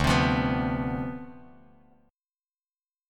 Dm13 Chord
Listen to Dm13 strummed